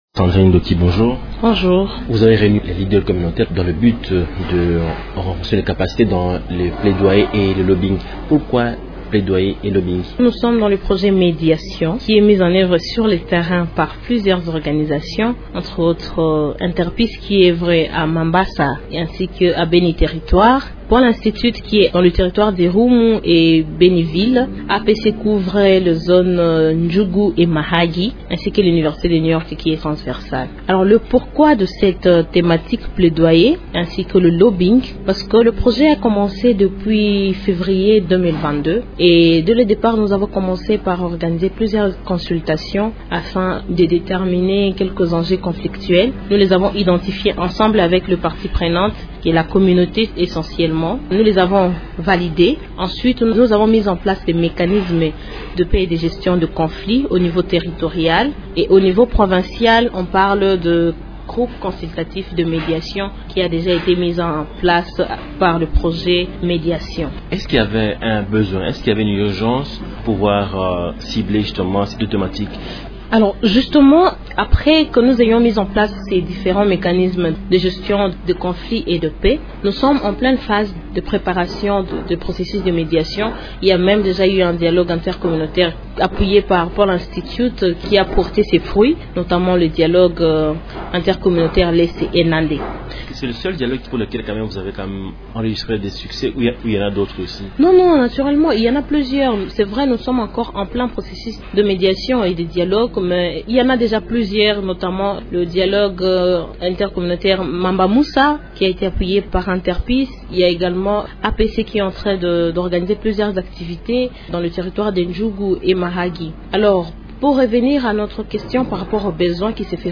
Elle est interrogée par